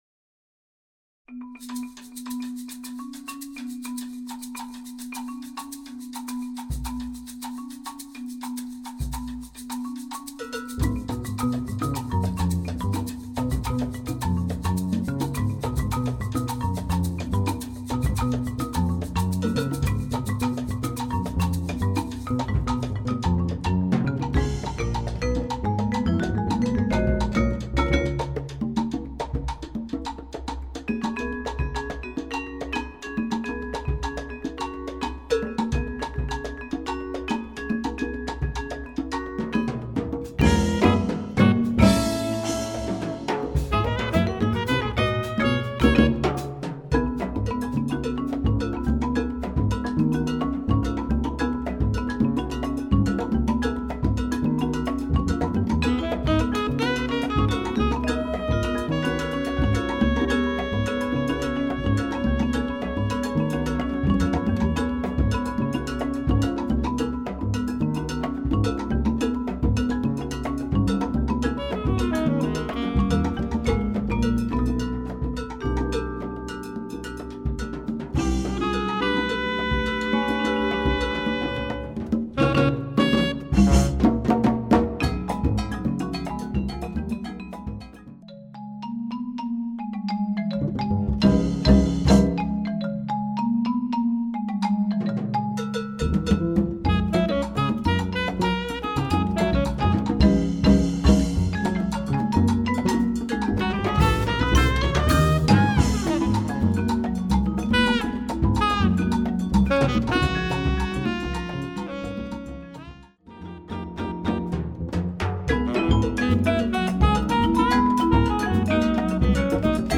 Category: percussion ensemble
Style: Latin funk
Solos: open